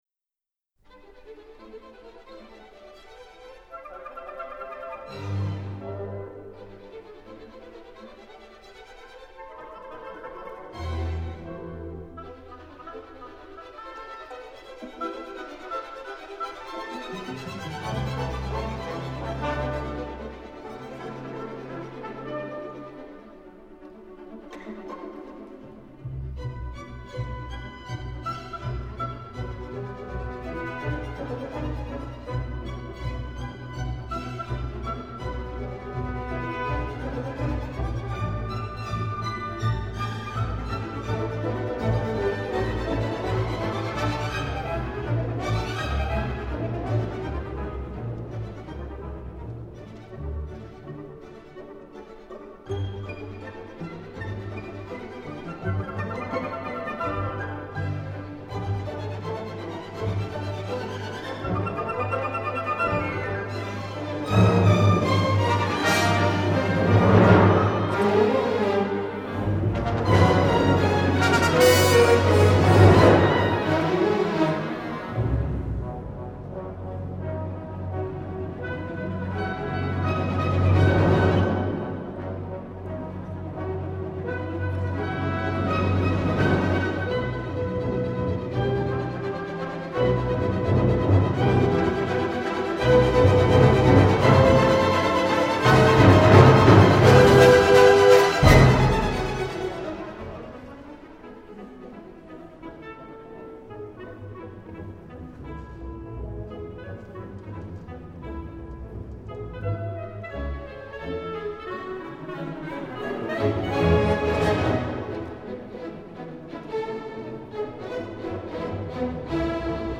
enregistrées lors d’une tournée au Japon en 1990